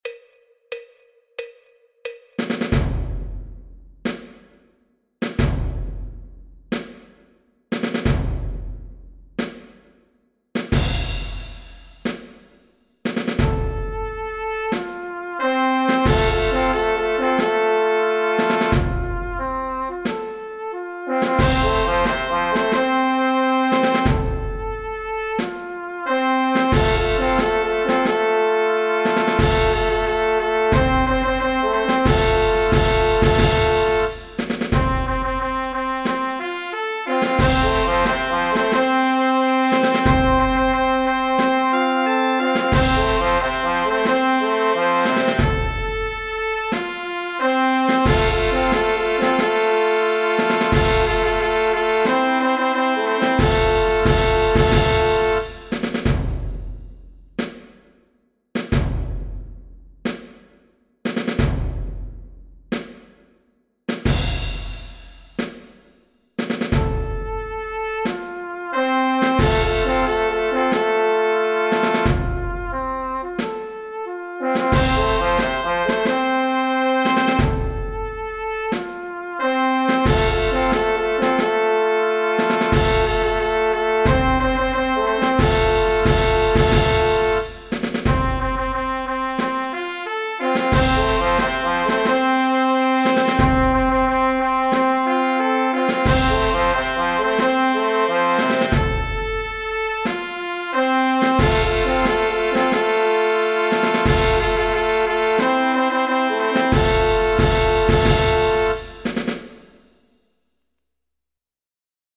Do Mayor (C) Marchas de Procesión
El MIDI tiene la base instrumental de acompañamiento.
Música clásica